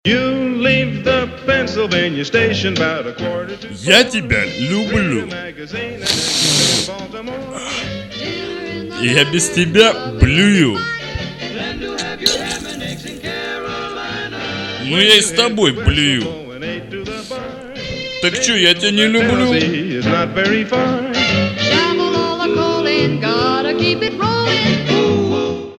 ГИТАРА, КЛАВИШНЫЕ, ВОКАЛ
БАС
УДАРНЫЕ
Записано на домашней студии